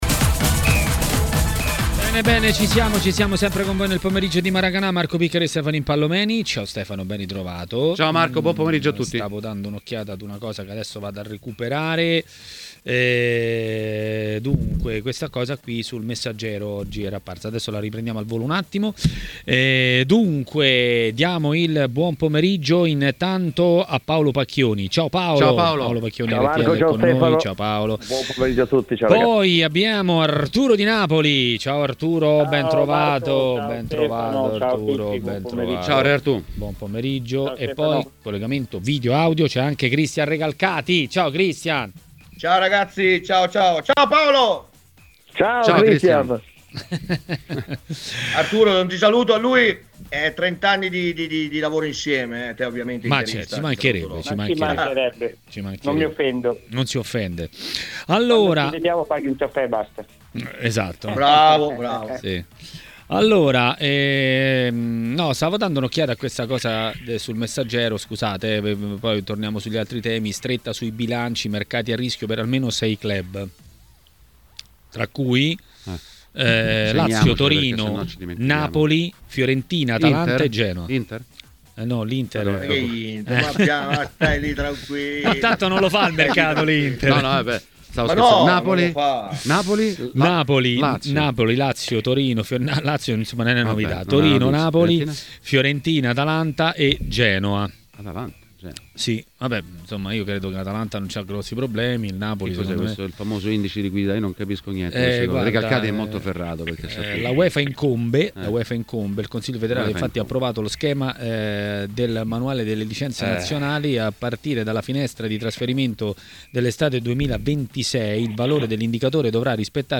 L'ex calciatore Arturo Di Napoli è stato ospite di Maracanà, nel pomeriggio di TMW Radio.